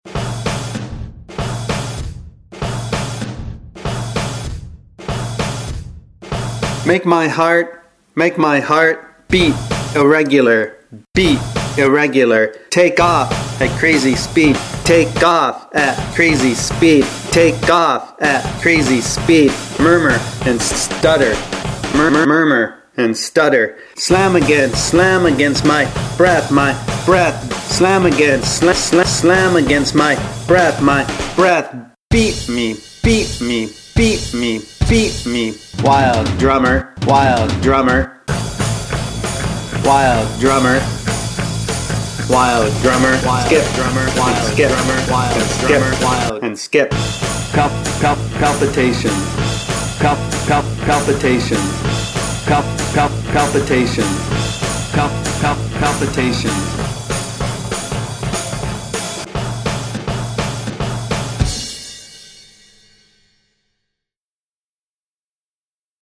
“Palpitations” is about the sensation you get standing close to loudspeakers. The lows that reverberate in your chest. The drumming that realigns your heart beat.